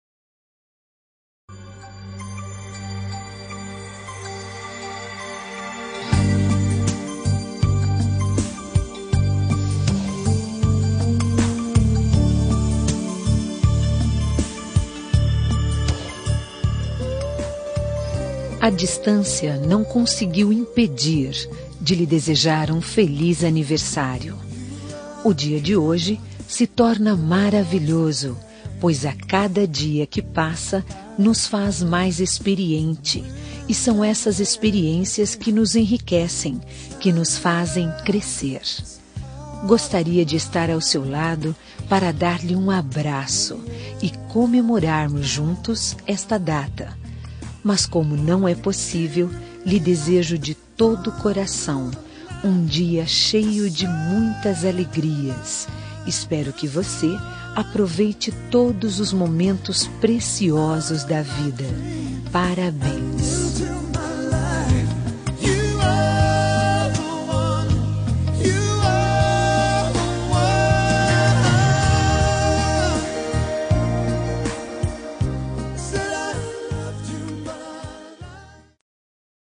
Aniversário de Pessoa Especial – Voz Feminina – Cód: 1900 – Distante
1900-pessoa-especial-distante-fem.m4a